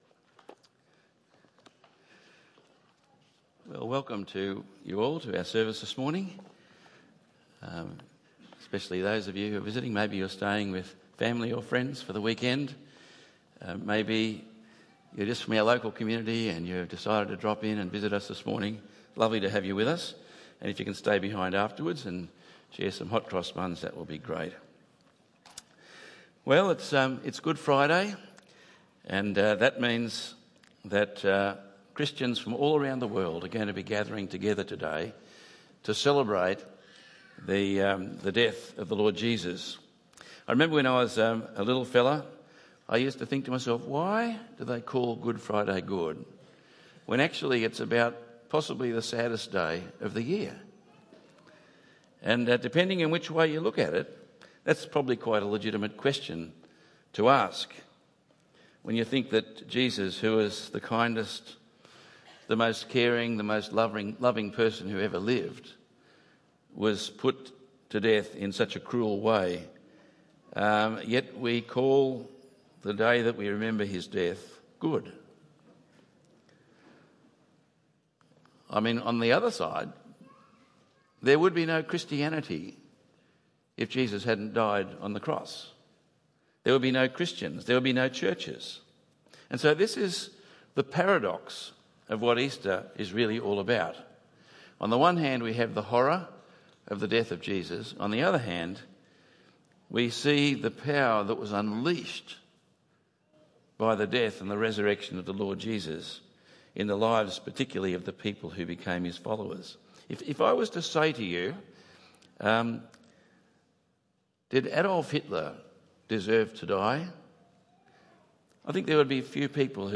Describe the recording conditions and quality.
Tagged with Sunday Morning